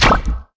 launch_shield_impact3.wav